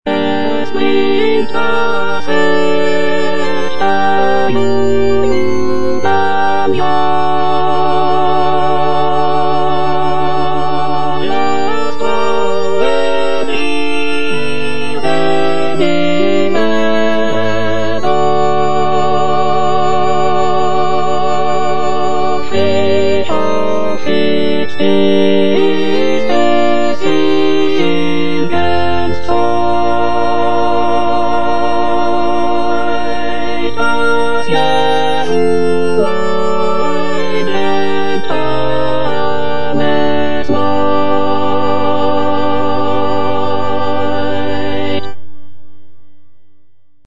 J.S. BACH - CANTATA "DAS NEUGEBORNE KINDELEIN" BWV122 Es bringt das rechte Jubeljahr - Alto (Emphasised voice and other voices) Ads stop: auto-stop
The music is filled with intricate melodies, rich harmonies, and expressive vocal lines, reflecting Bach's mastery of composition.